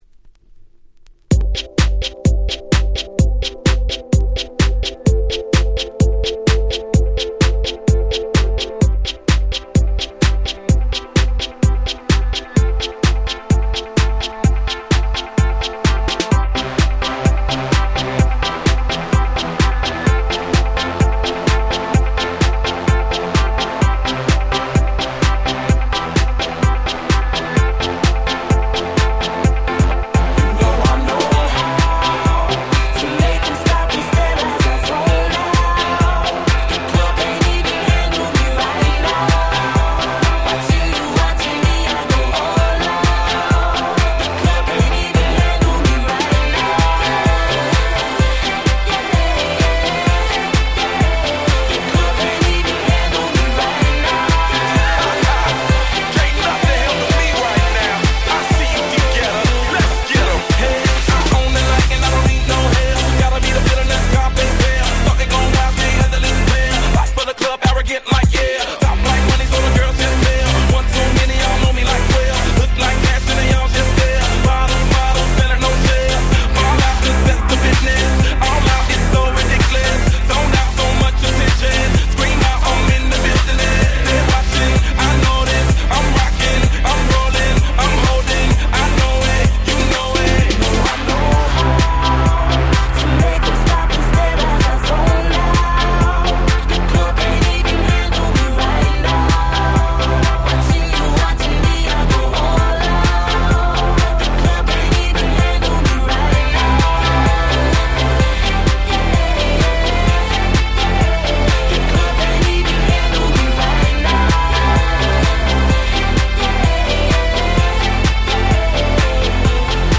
フローライダ デヴィットゲッタ EDM アゲアゲ パリピ 10's